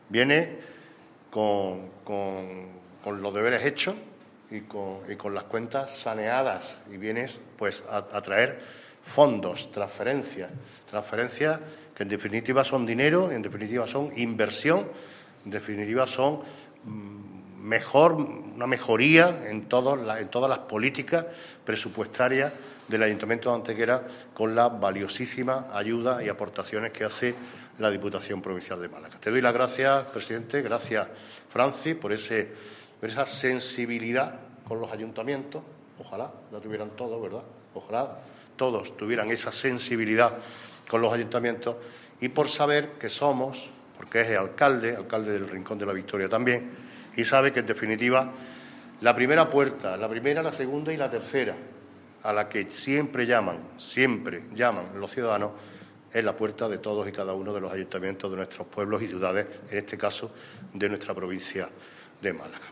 Así lo ha anunciado este mediodía en el Ayuntamiento de Antequera el presidente de la Diputación, Francisco Salado, en una rueda de prensa en la que ha estado acompañado por el alcalde de Antequera, Manolo Barón, así como por el concejal antequerano y a su vez diputado provincial Juan Álvarez.
Cortes de voz